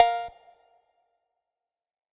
Perc (Bellz).wav